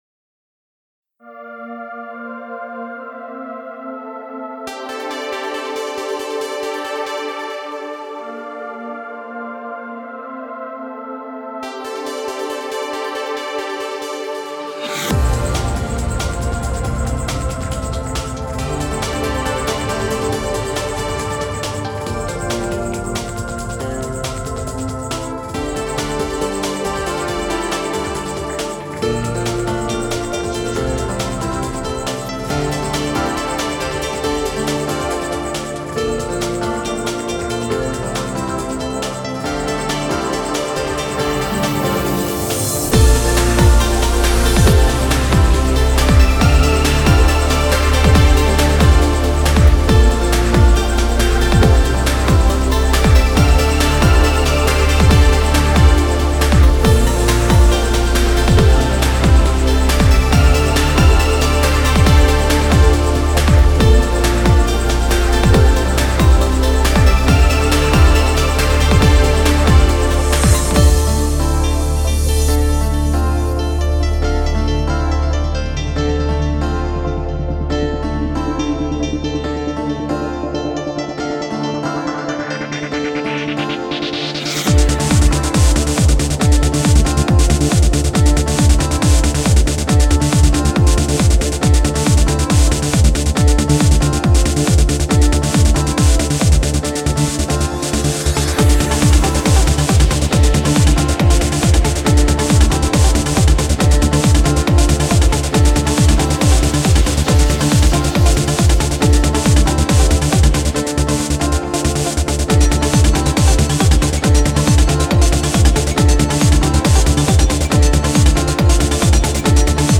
Genre: Indie.